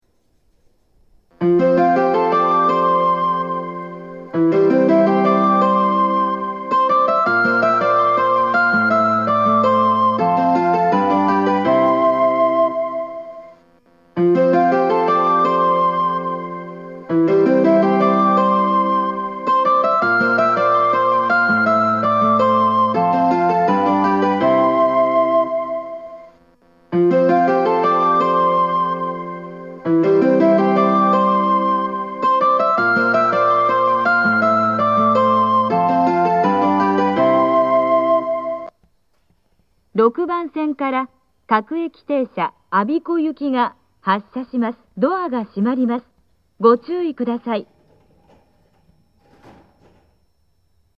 発車メロディー 比較的曲は長いですが、余韻までは一番鳴りやすいです。
5,6番線の収録は久住・下総松崎寄りが静かでおススメです。